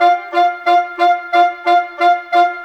Rock-Pop 07 Winds 03.wav